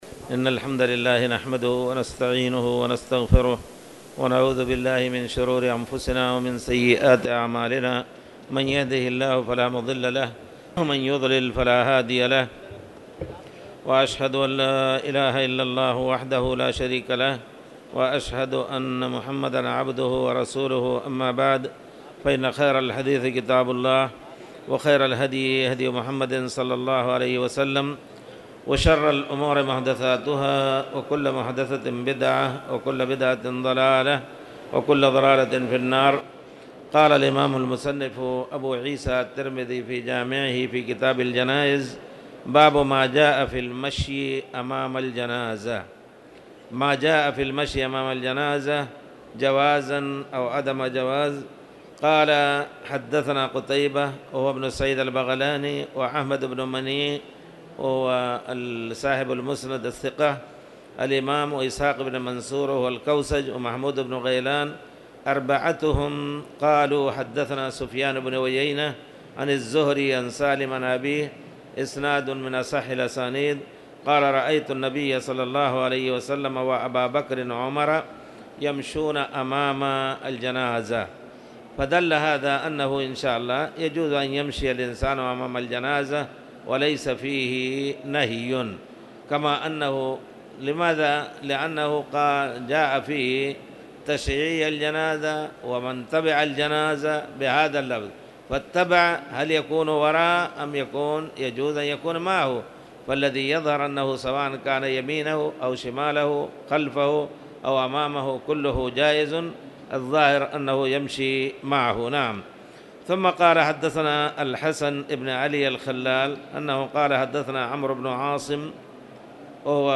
تاريخ النشر ١١ رمضان ١٤٣٧ هـ المكان: المسجد الحرام الشيخ